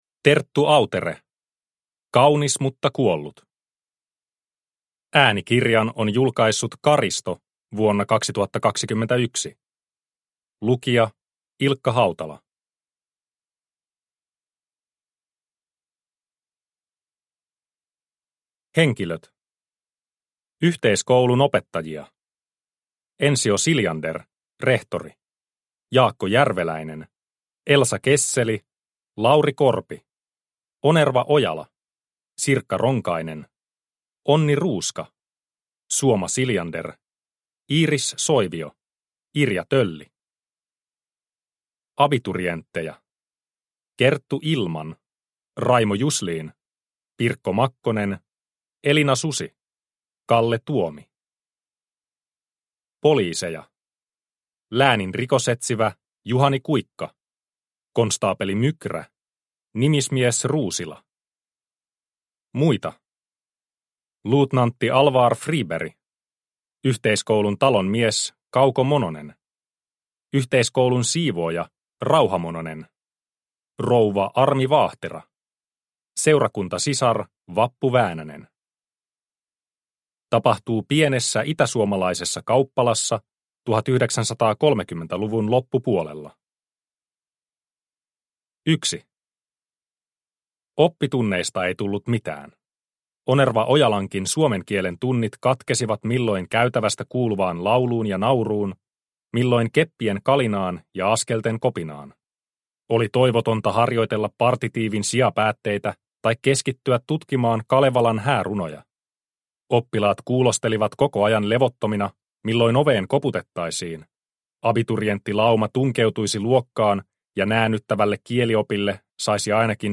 Kaunis mutta kuollut – Ljudbok – Laddas ner